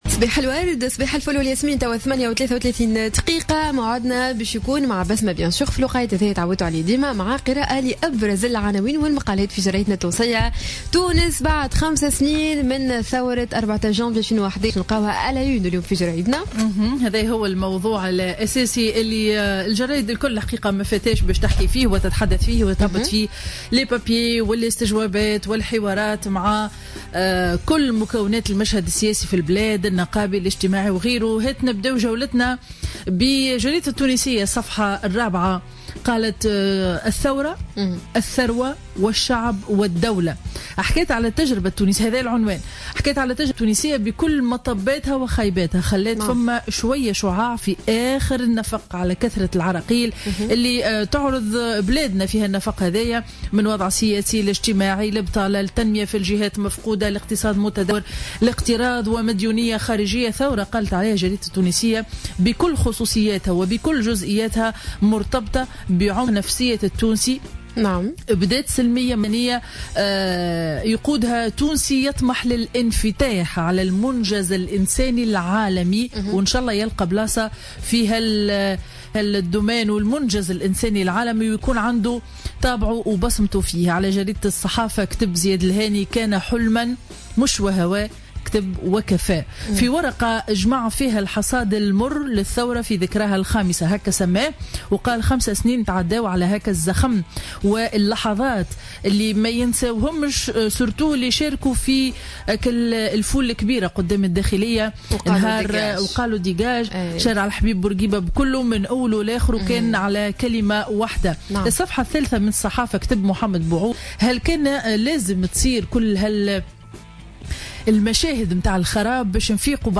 Revue de presse du jeudi 14 janvier 2016